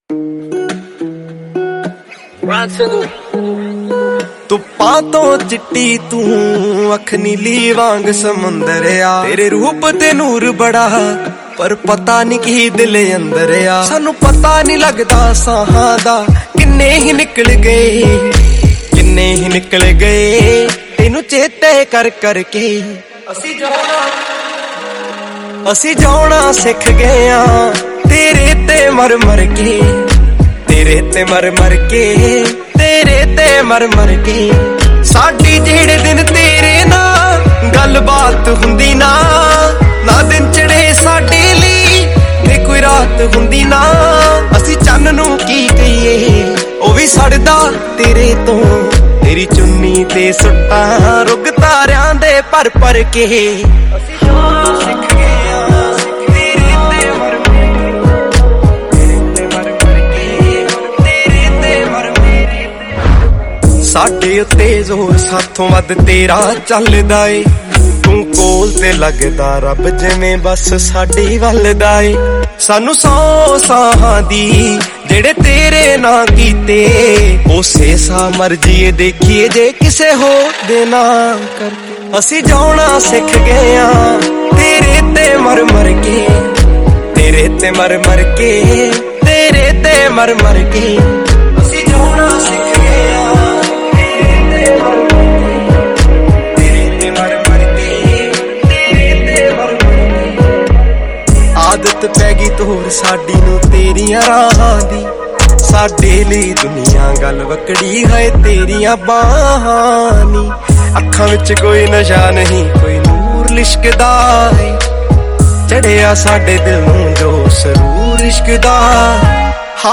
Punjabi Single Track song